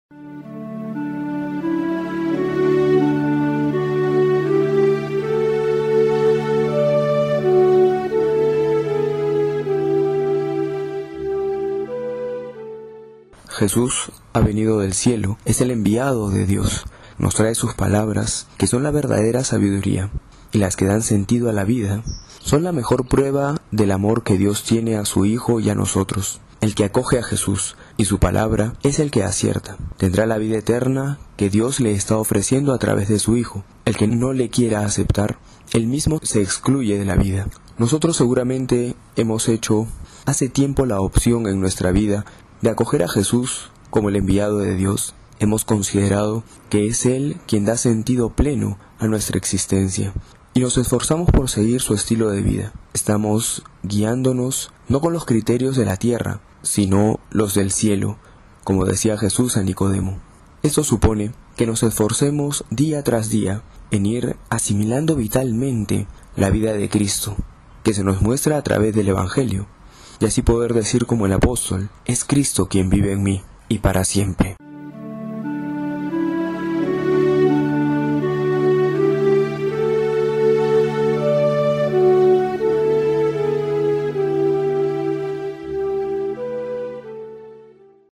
Homilía para hoy: Juan 3,31-36